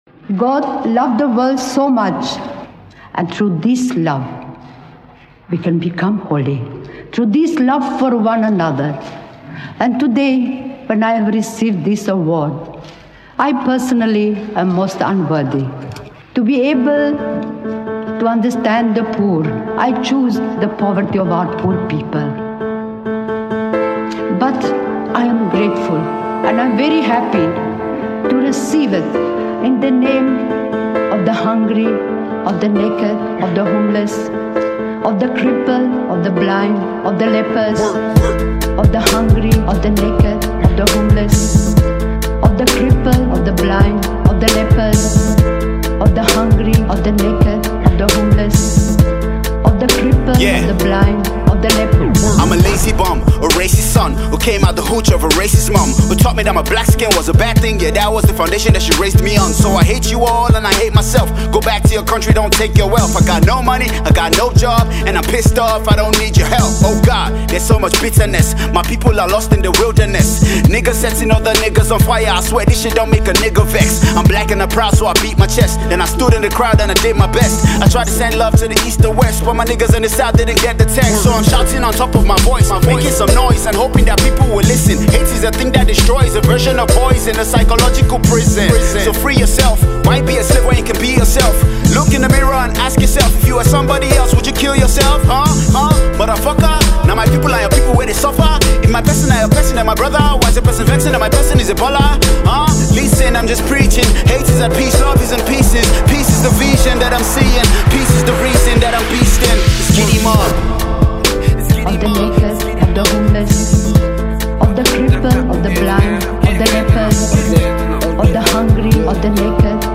esteemed member of the new Hip Hop movement
conscious rap song